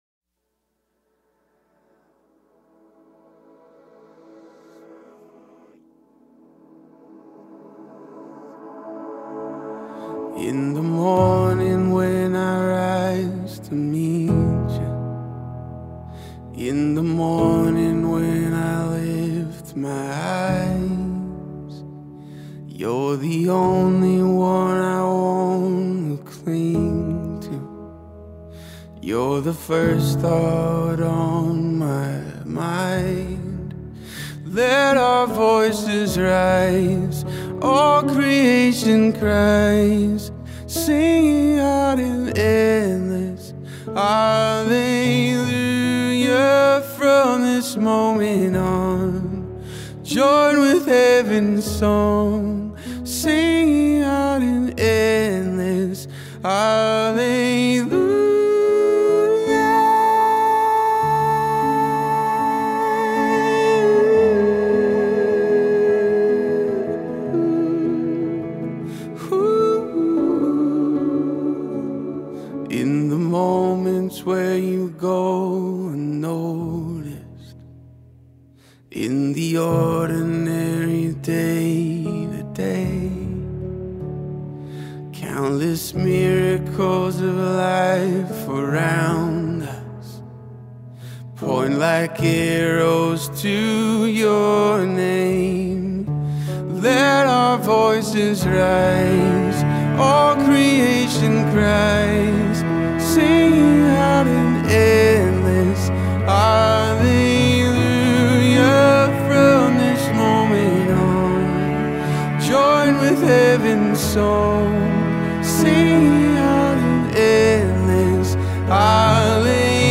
207 просмотров 173 прослушивания 3 скачивания BPM: 77